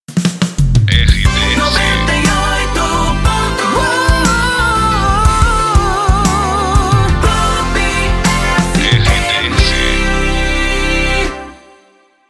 Passagem